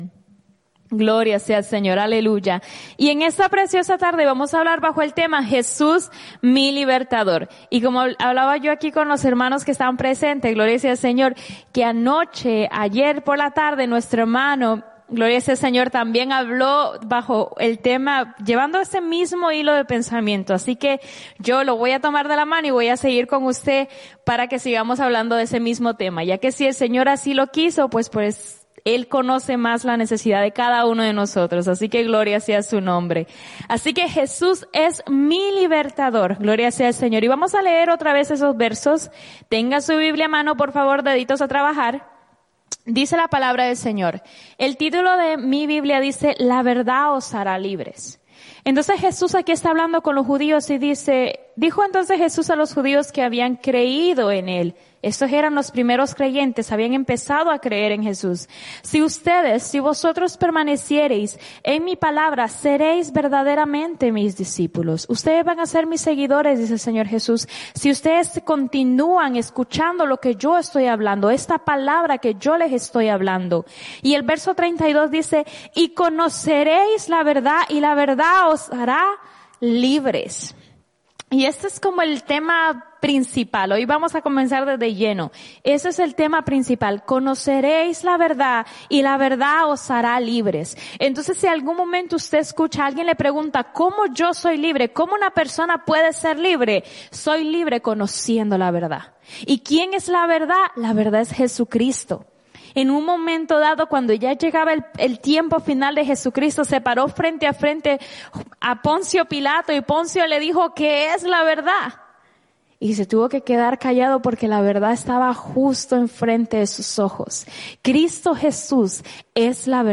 en la Iglesia Misión Evangélica en Souderton, PA